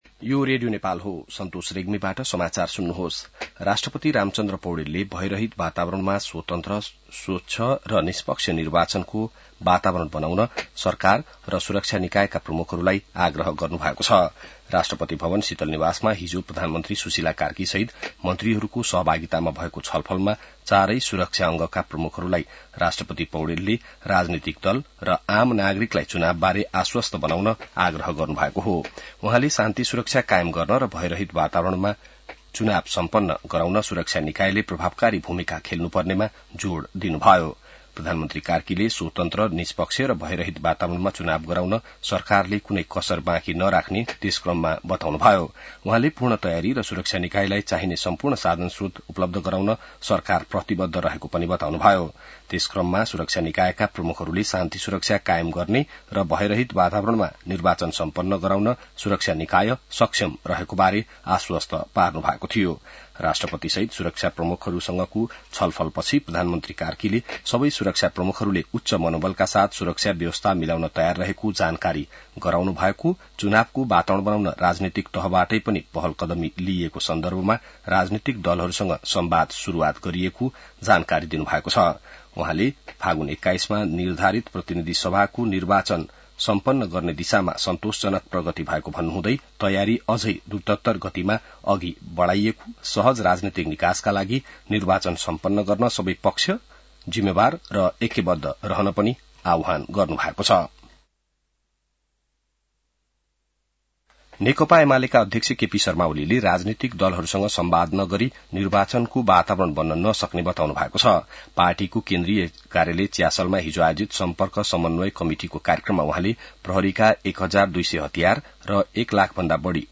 बिहान ६ बजेको नेपाली समाचार : २ कार्तिक , २०८२